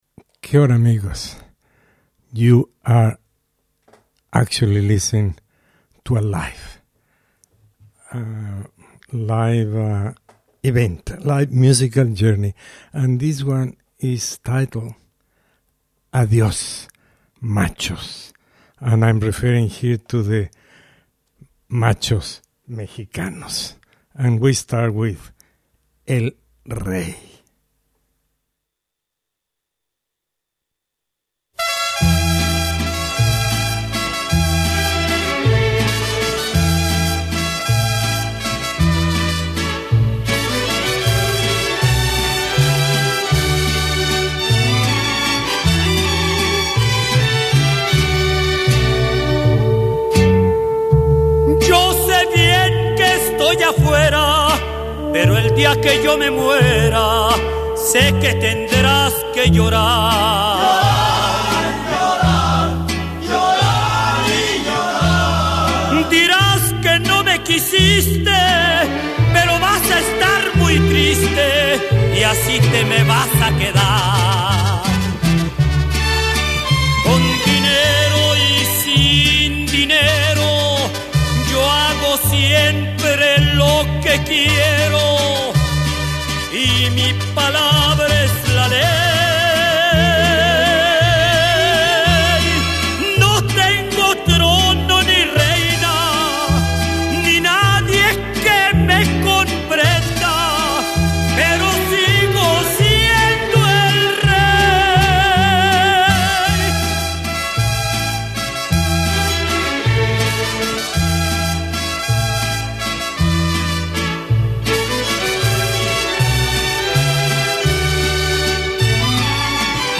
Mexican Folk > Mariachi & Rancheras.